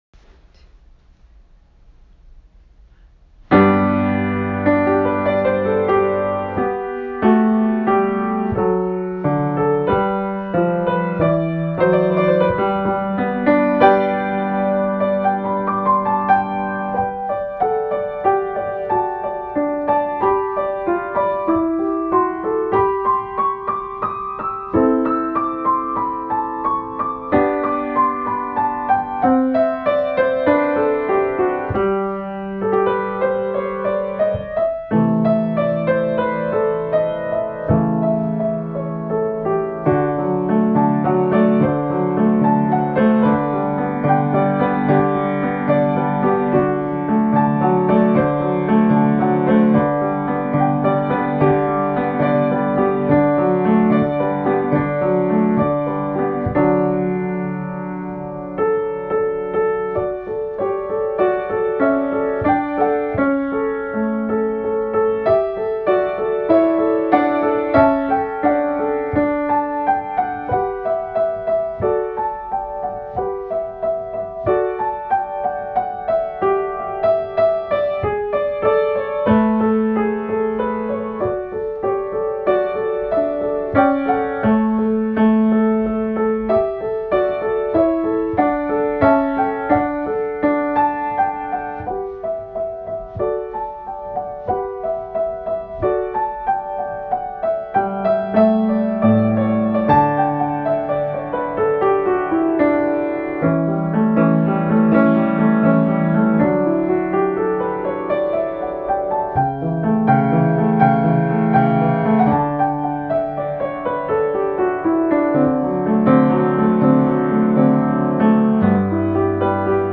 Beethoven Piano Sonata in G